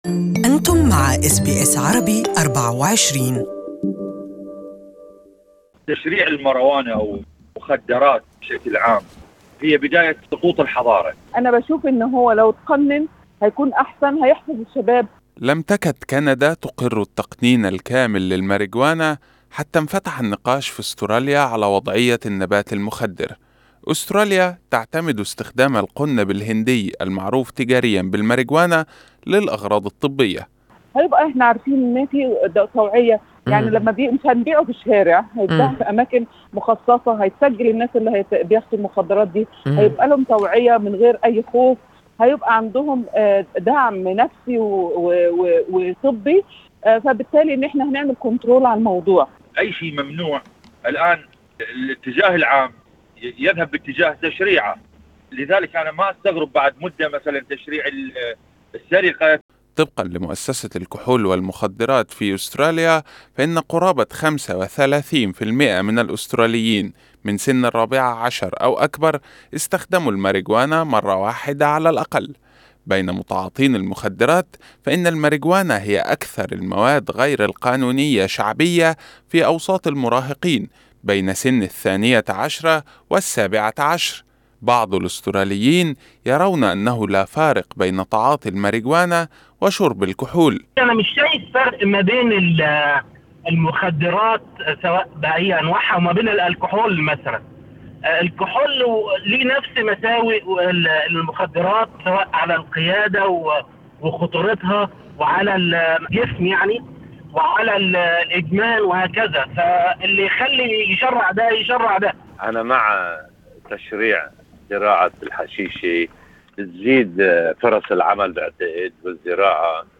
انقسم أفراد الجالية العربية بشأن التقنين الكامل بين مؤيد ومعارض ( أصوات الطرفين في الرابط الصوتي أعلاه ).